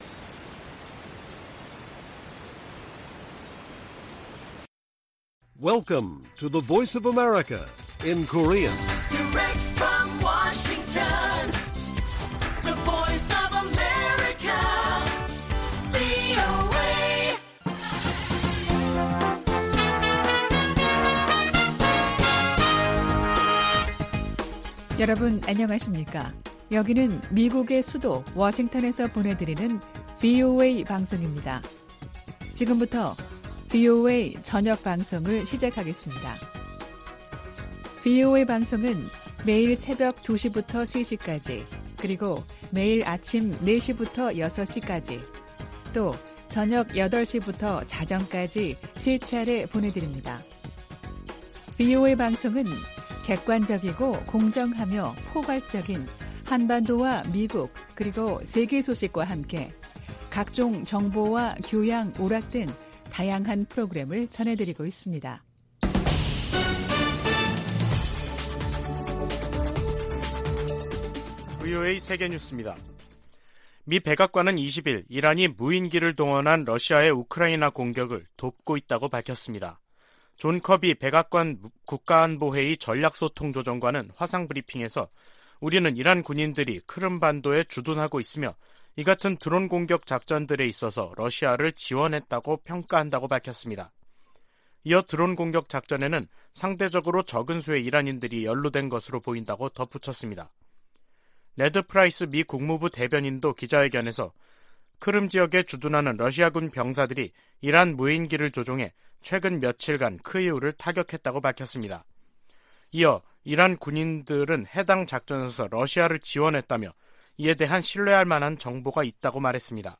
VOA 한국어 간판 뉴스 프로그램 '뉴스 투데이', 2022년 10월 21일 1부 방송입니다. 조 바이든 미국 대통령은 모든 방어역량을 동원해 한국에 확장억제를 제공하겠다는 약속을 확인했다고 국무부 고위당국자가 전했습니다. 미 국방부가 북한의 잠재적인 추가 핵실험 준비 움직임을 주시하고 있다고 밝혔습니다. 미국 일각에서는 북한의 핵 보유를 인정하고 대화로 문제를 풀어야 한다는 주장이 나오지만 대다수 전문가들은 정당성을 부여해서는 안된다는 입장입니다.